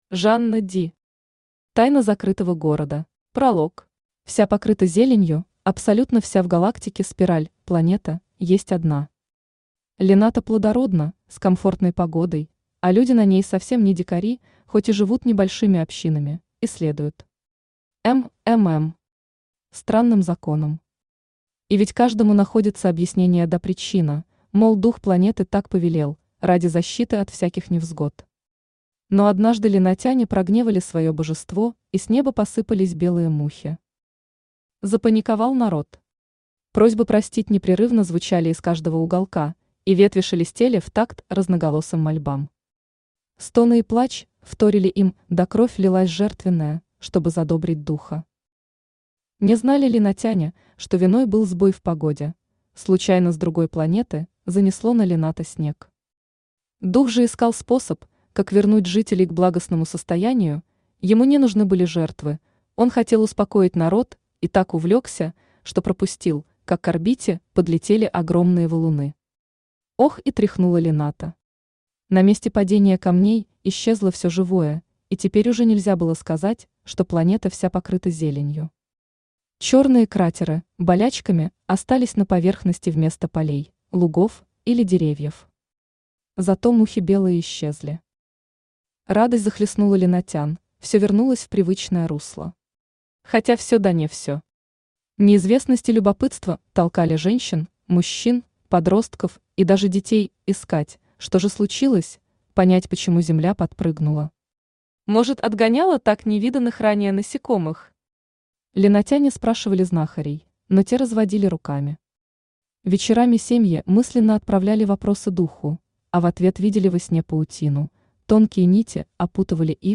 Aудиокнига Тайна закрытого города Автор Жанна Ди Читает аудиокнигу Авточтец ЛитРес.